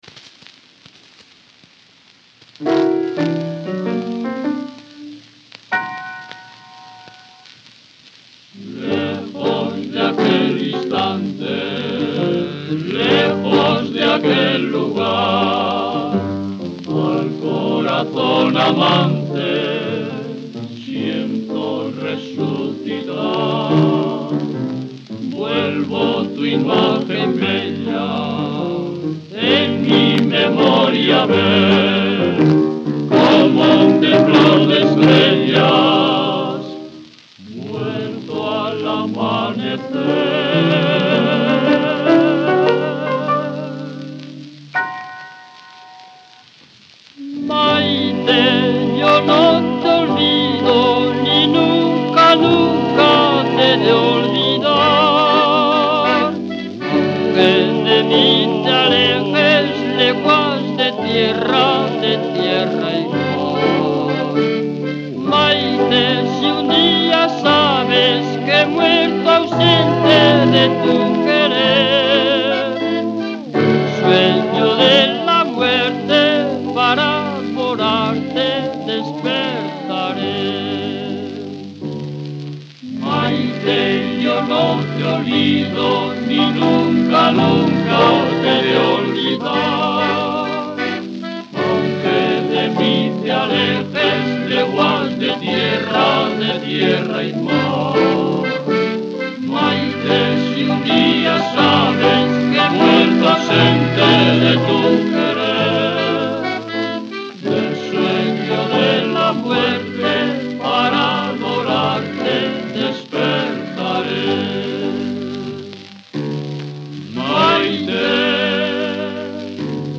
Maite : zorzico de la película Jai-Alai
by Los Xey | Odeon, 1942